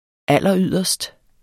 Udtale [ ˈalˀʌˈyðˀʌsd ]